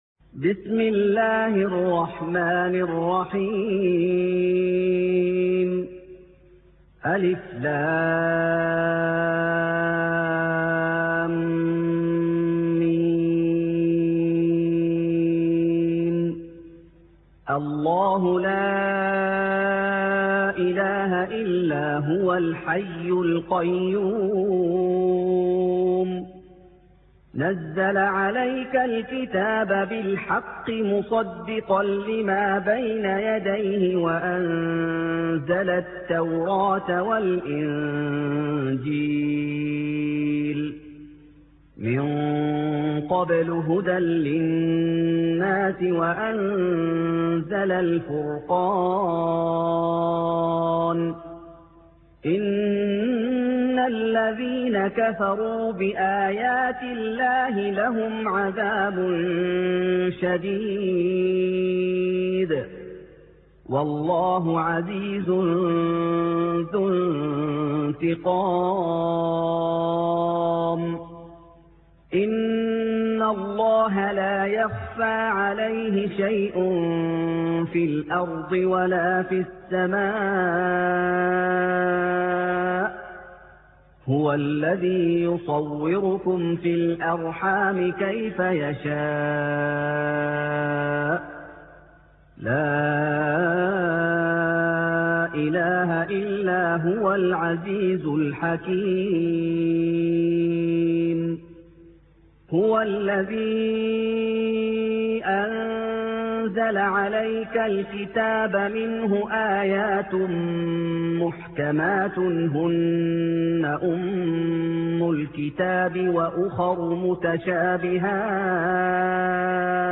سُورَةُ ٓآلِ عِمۡرَانَ بصوت الشيخ محمد ايوب